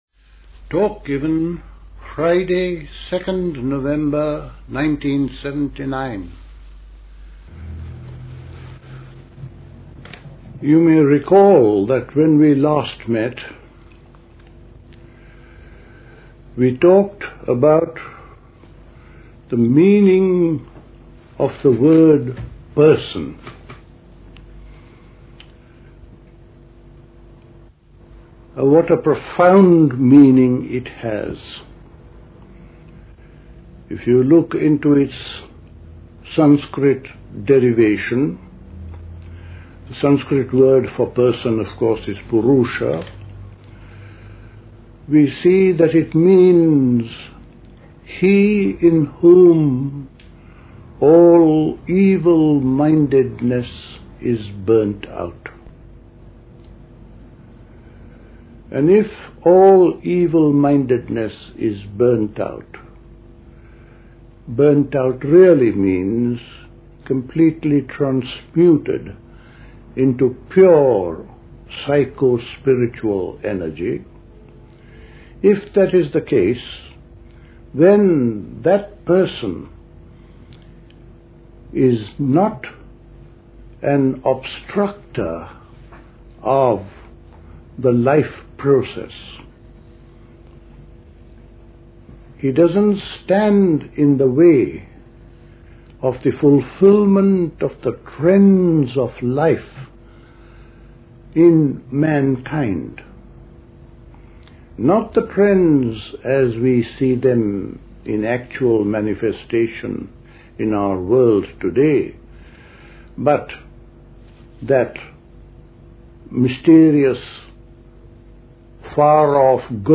A talk
at Dilkusha, Forest Hill, London on 2nd November 1979